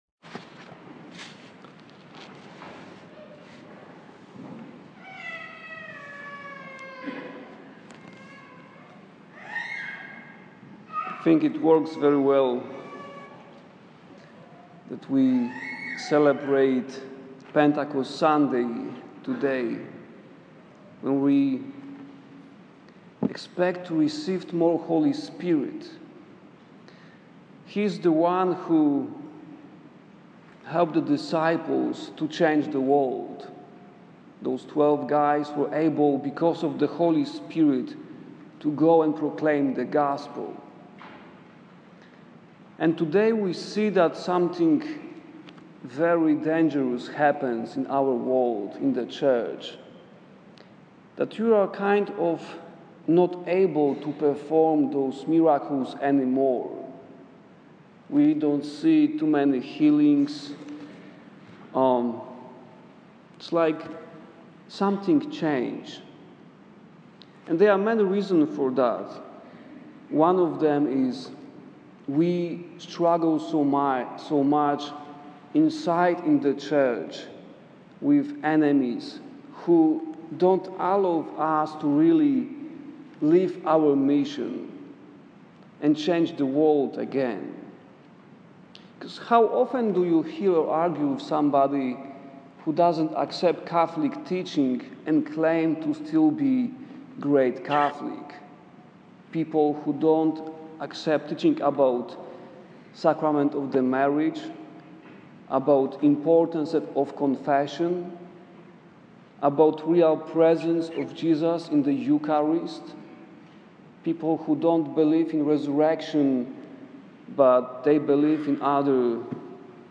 Not an easy homily for Pentecost Sunday